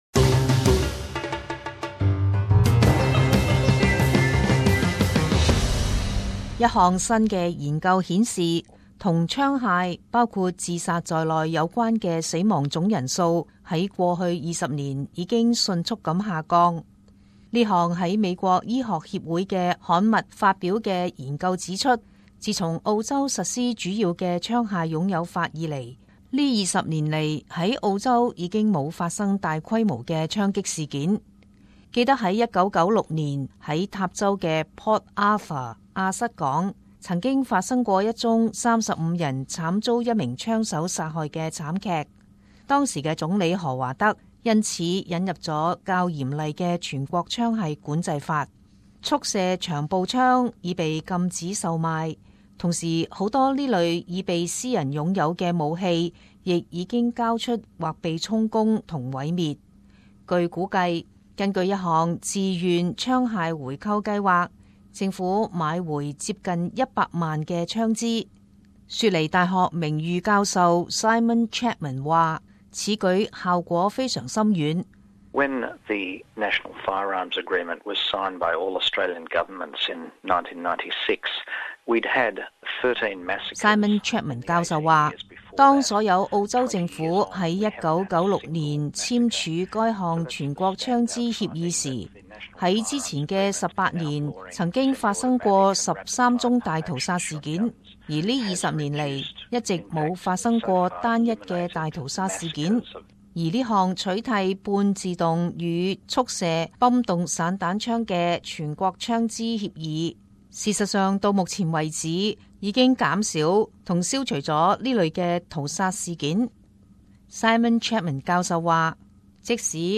時事報導 - 澳洲槍枝管制法成美國榜樣